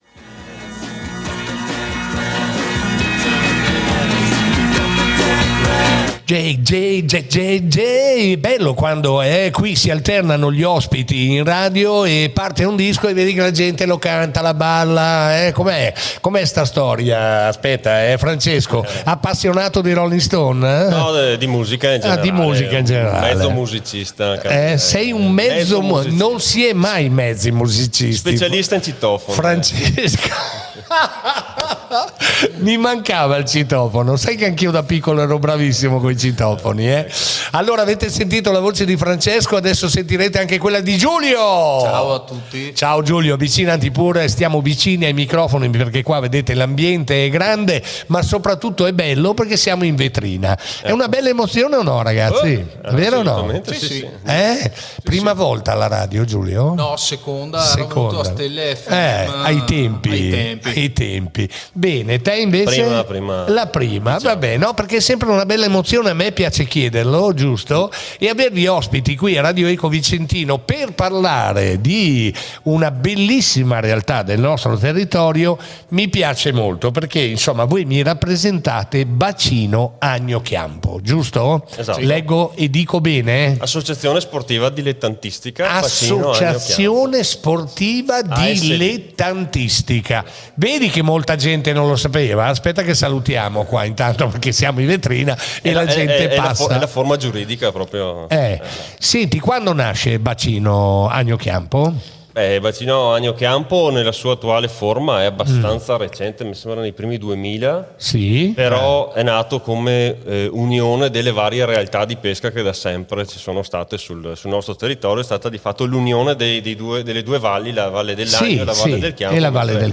Innanzitutto ringraziamo il comune di Valdagno che si sta adoperando per garantirci gli spazi per una nuova sede, nella quale contiamo di incontrarvi tutti quanto prima, e per l’invito alla trasmissione “On Air” di Radio Eco Vicentino nella quale abbiamo potuto farci conoscere e portare avanti la nostra missione di promozione della pesca come attività sportiva individuale o collettiva, etica e sostenibile. Qui il podcast , al minuto 51, oppure qui l’audio diretto ritagliato senza gli inframmezzi musicali.
Radio-Eco-Vicentino.mp3